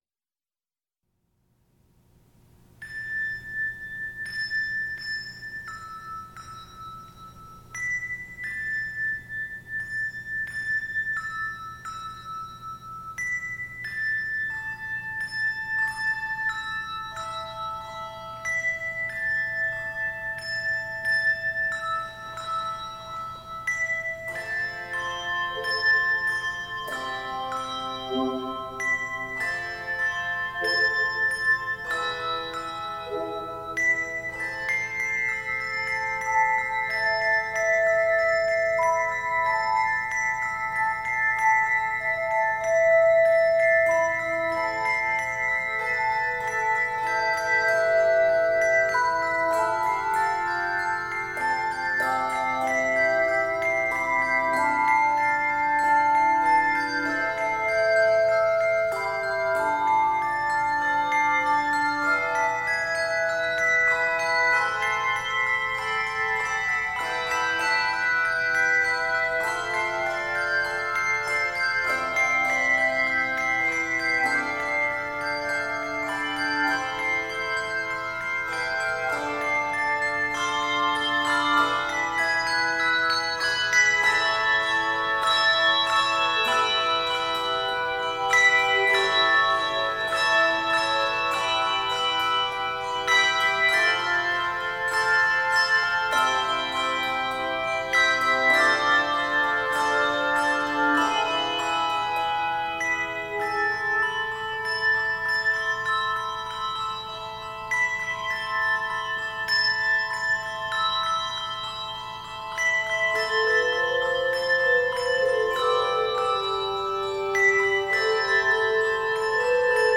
haunting and introspective piece
Key of a minor.
Octaves: 3-5